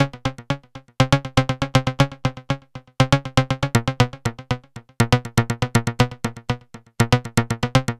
TSNRG2 Lead 008.wav